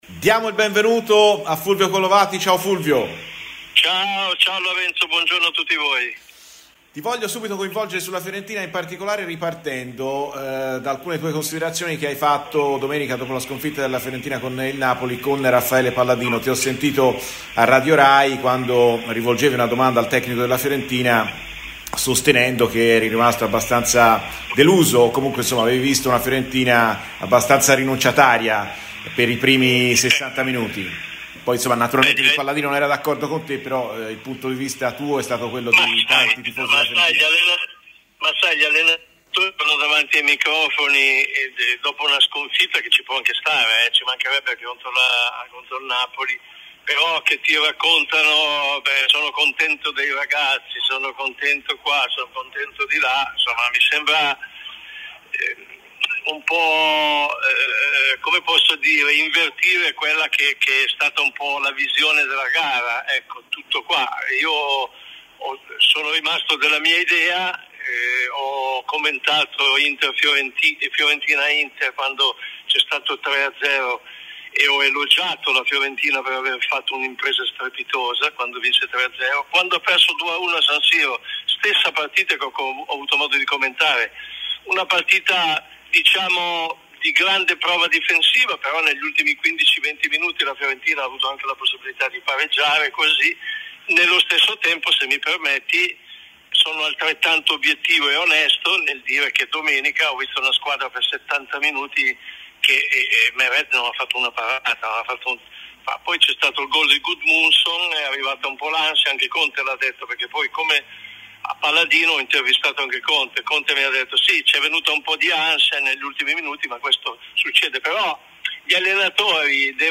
Fulvio Collovati, ex calciatore e dirigente sportivo italiano è intervenuto a Radio Firenze Viola durante la trasmissione "Viola Amore mio" per parlare dell'attuale situazione della Fiorentina: "Gli allenatori non possono dire che sono contenti quando perdono, ho commentato entrambe le gare contro l'Inter e sono rimasto stupito in positivo dalla prestazione dei viola sia nella gara d'andata sia nella gara di ritorno dove la Fiorentina poteva pure pareggiare.